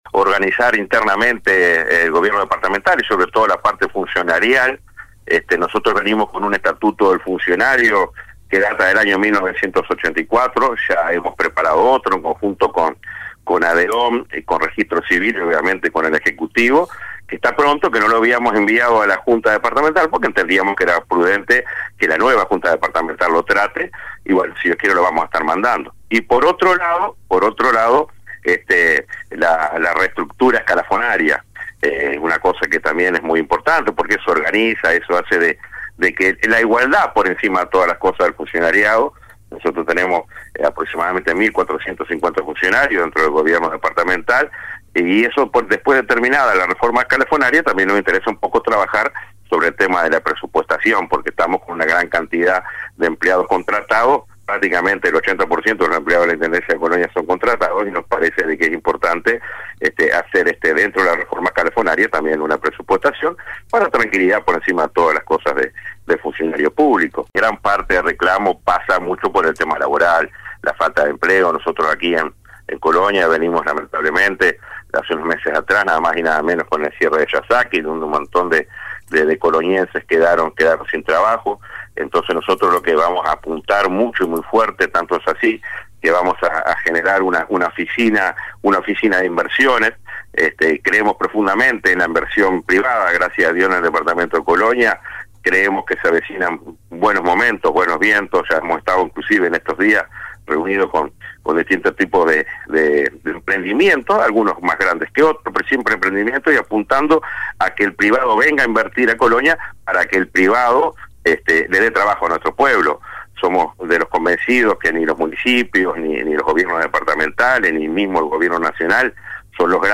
Así lo indicó en una entrevista con radio Monte Carlo de Montevideo, el intendente electo de Colonia Guillermo Rodríguez.